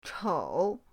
chou3.mp3